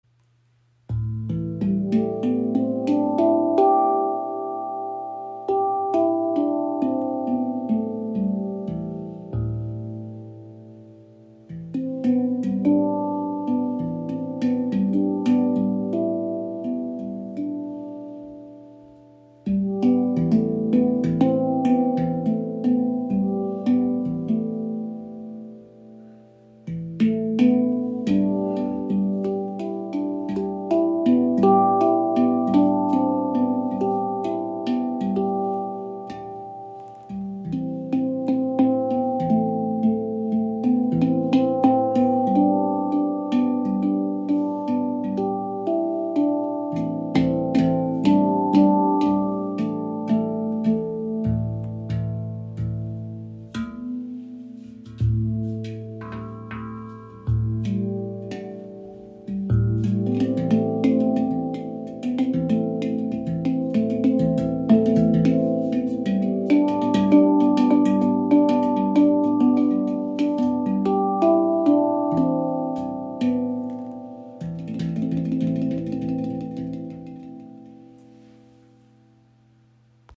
Kraftvoller, tiefer Klang – ideal für Meditation, Rituale und Klangreisen.
Klangbeispiel
Ihr warmer, tiefer Klang und die keltisch anmutende Stimmung laden zu meditativen Klangreisen ein. Hergestellt aus Ember Edelstahl, überzeugt sie mit langem Sustain und edler Klangfarbe – ideal für bewusstes, ruhiges Spiel und berührende musikalische Momente.